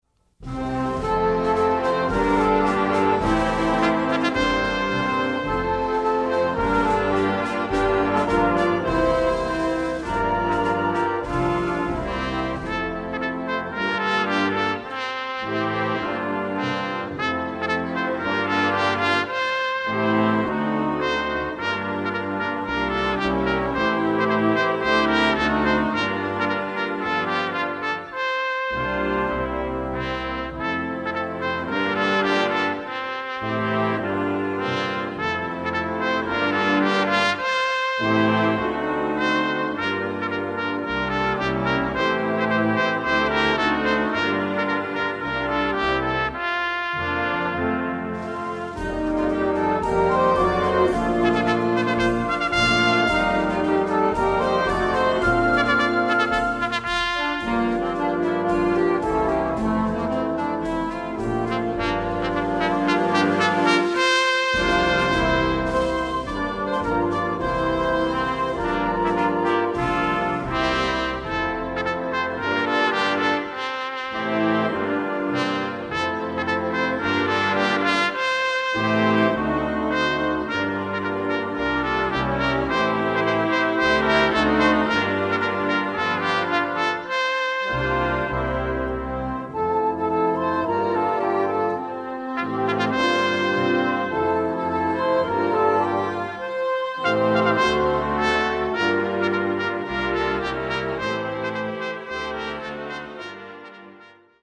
bright and cheery piece for trumpet solo and band